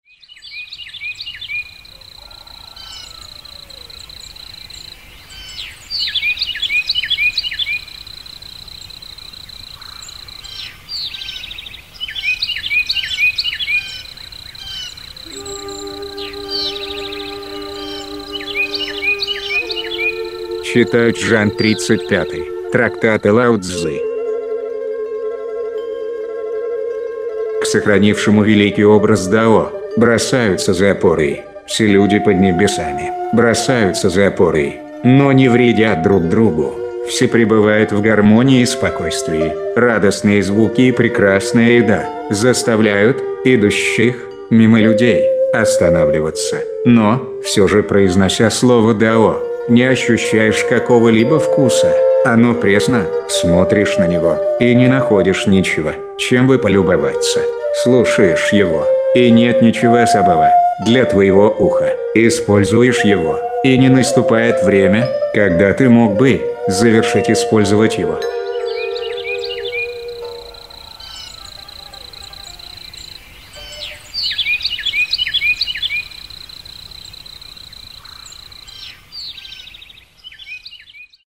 Аудиокнига: Трактат о Дао и Дэ